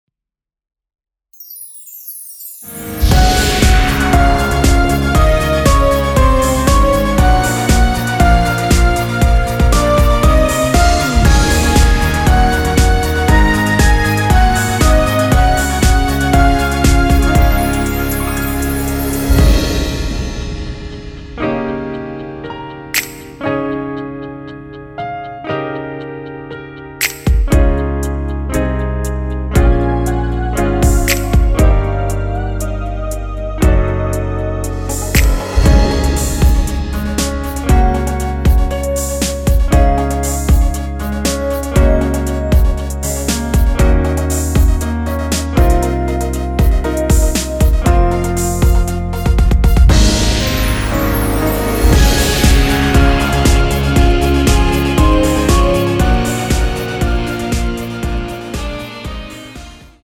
원키에서(-2)내린 MR입니다.
Db
앞부분30초, 뒷부분30초씩 편집해서 올려 드리고 있습니다.